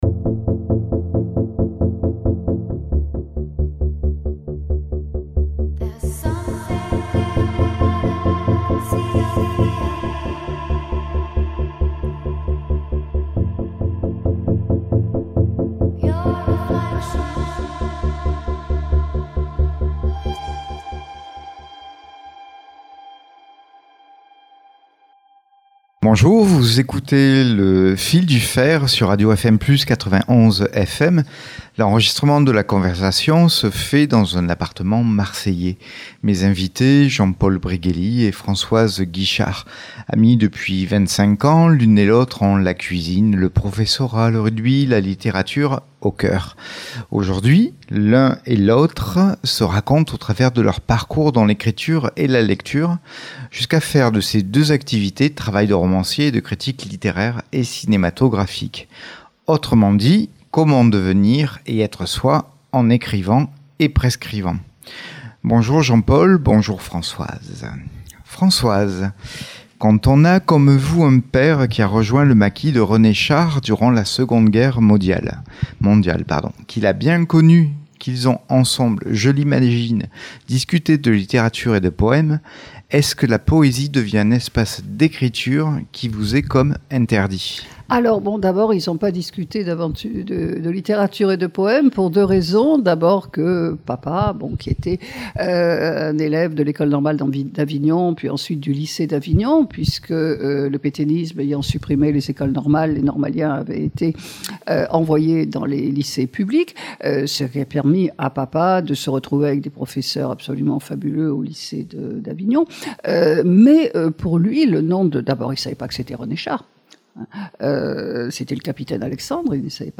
L’enregistrement de la conversation se fait dans un appartement marseillais.